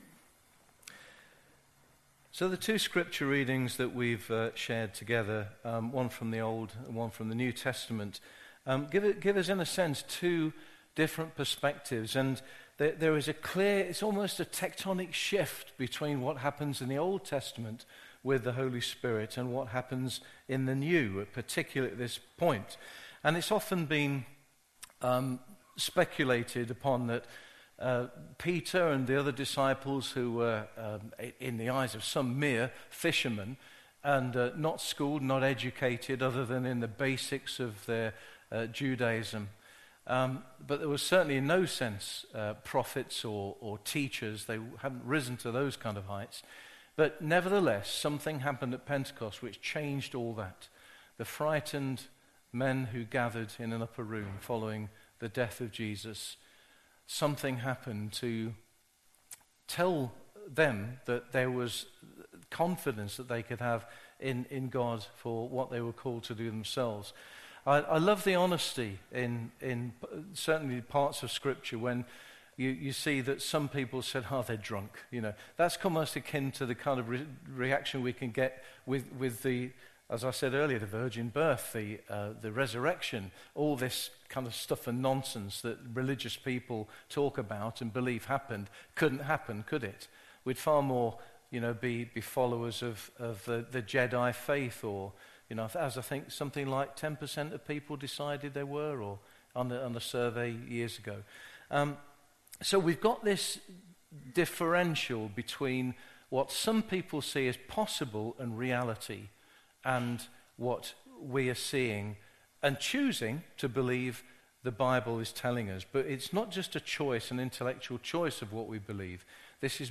An audio version of the sermon is also available.
Service Type: Sunday Morning
05-19-sermon.mp3